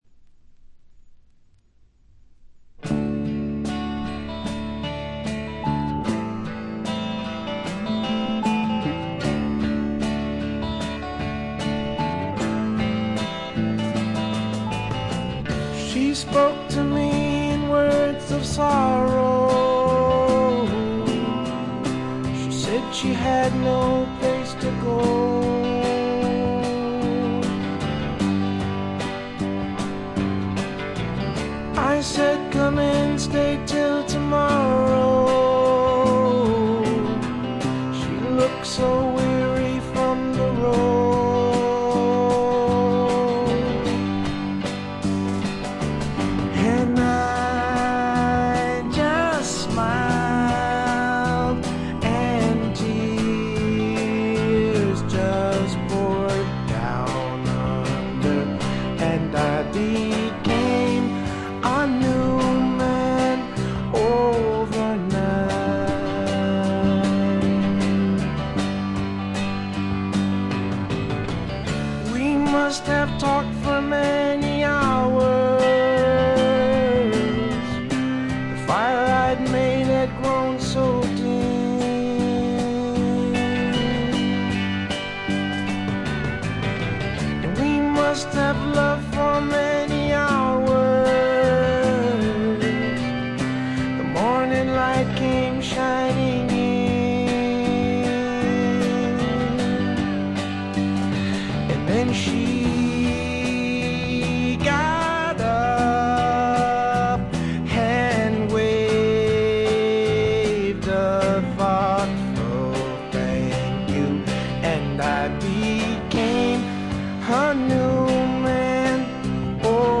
カントリー風味を加えています。カントリーロック的な曲とフォークロック的な曲の組み合わせ加減もとてもよいです。
Guitar, Guitar (Rhythm), Vocals